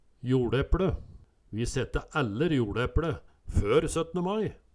Høyr på uttala Ordklasse: Substantiv inkjekjønn Kategori: Planteriket Jordbruk og seterbruk Attende til søk